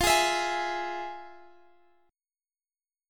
Listen to FmM7 strummed